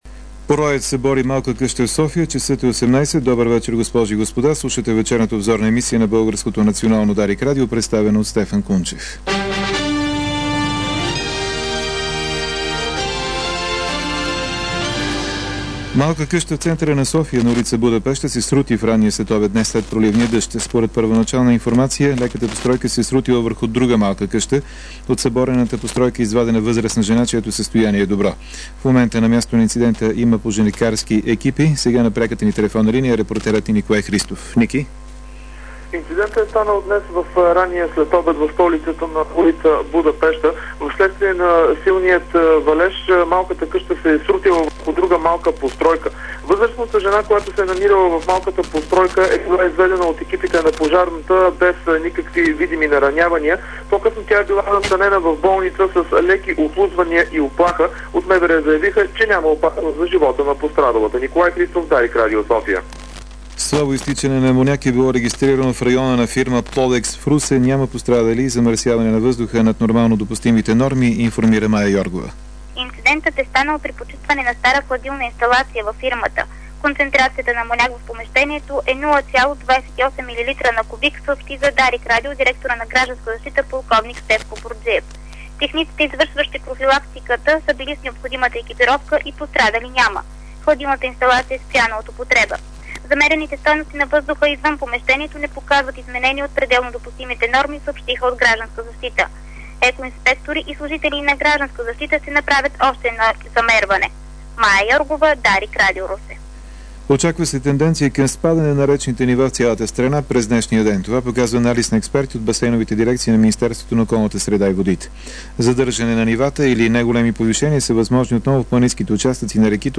DarikNews audio: Обзорна информационна емисия – 28.05.2006